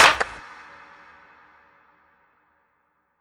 TM-88 Claps [Birds].wav